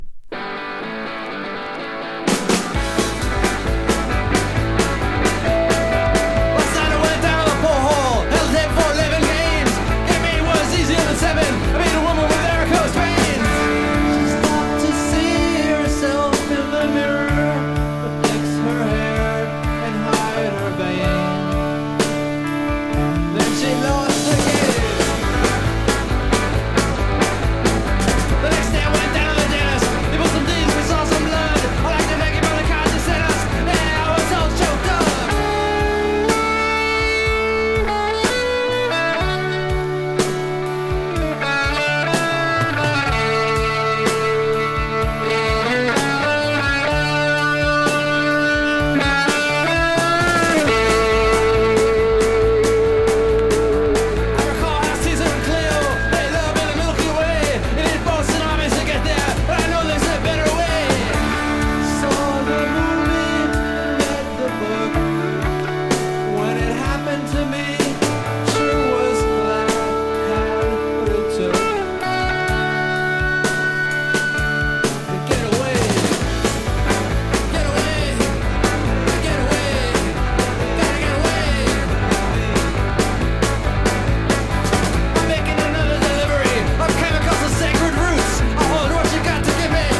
アコギのストロークが最高。